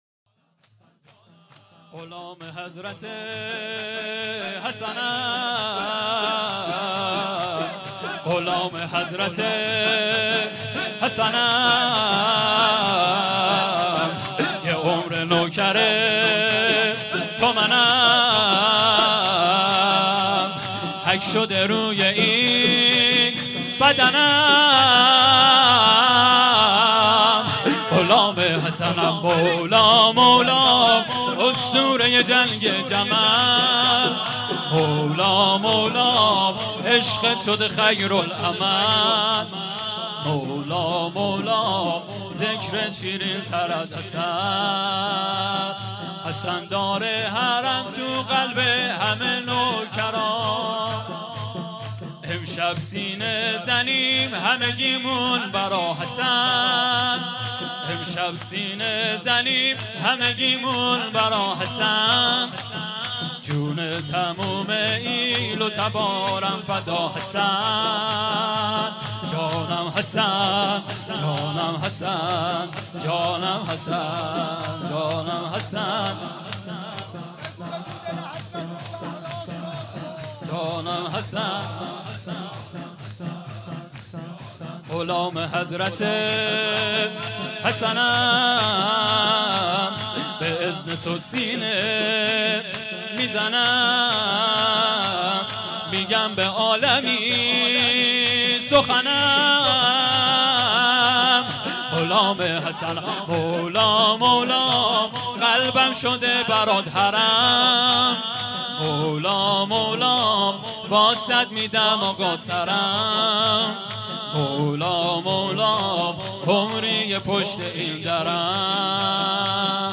شور
شب پنجم محرم 1440